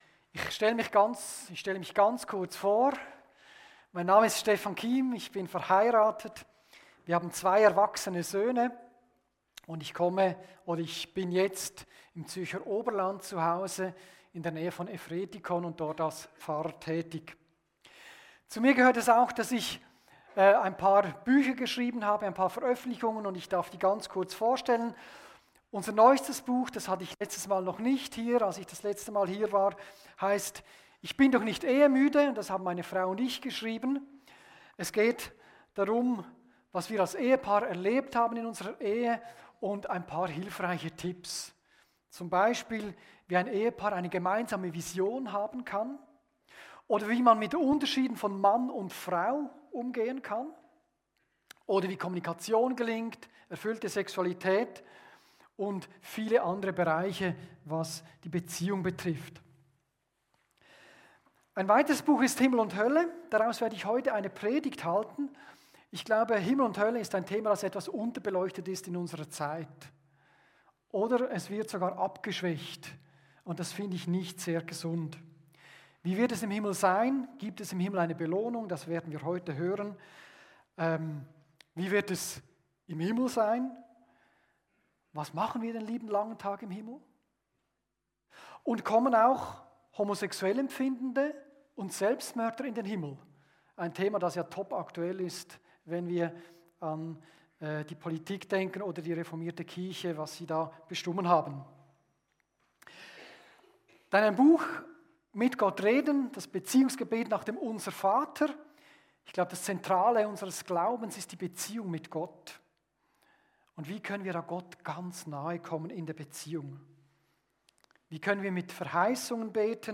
Kategorie: Predigt